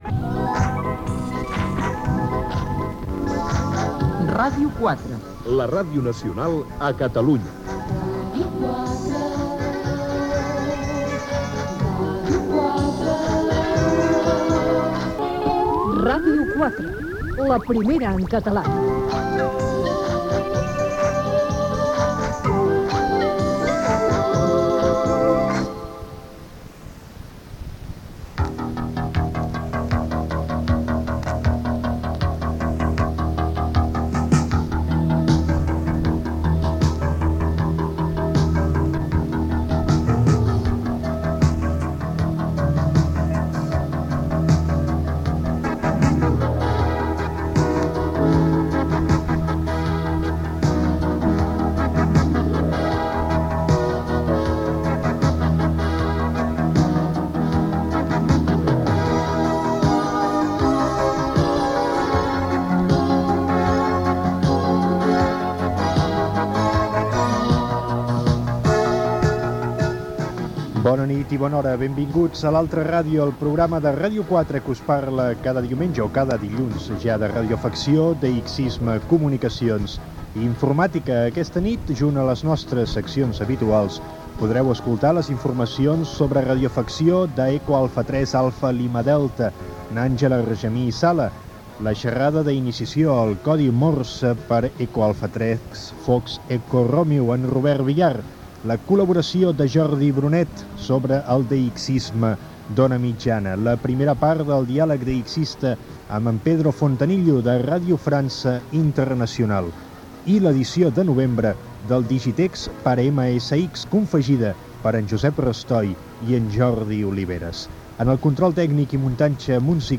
Gènere radiofònic Divulgació
Banda FM